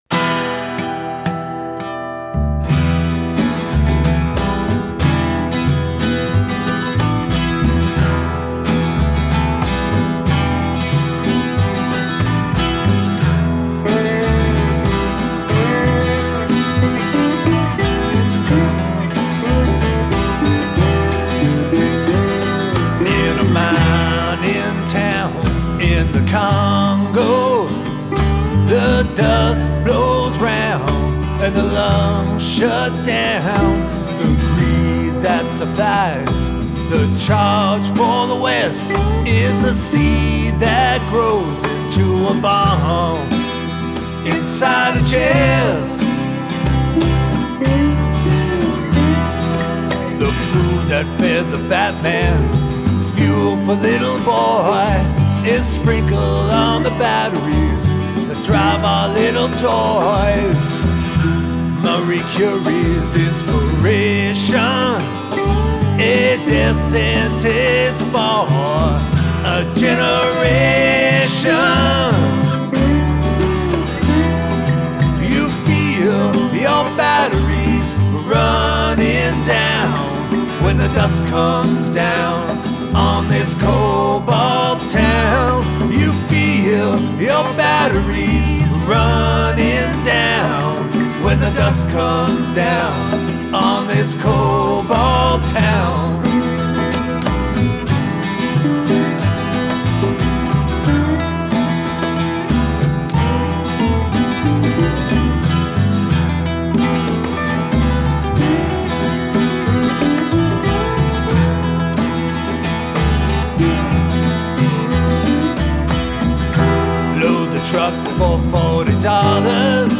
guitars and vocals